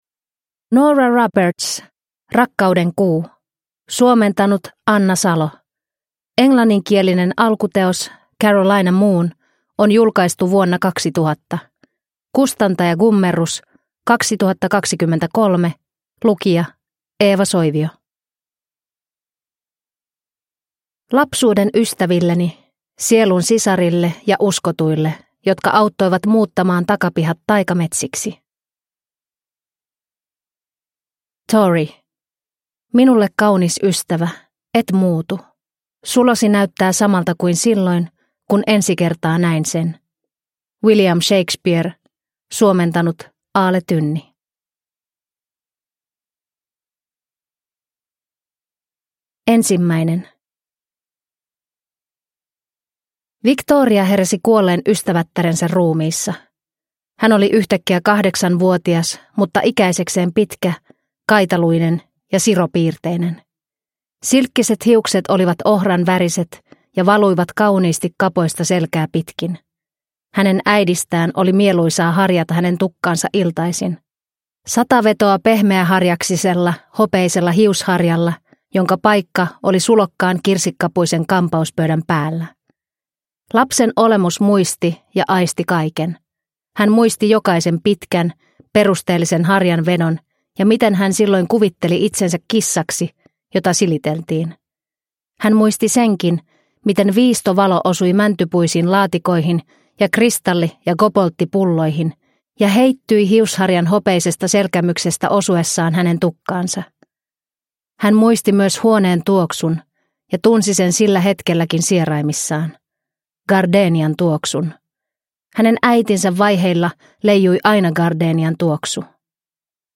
Rakkauden kuu – Ljudbok – Laddas ner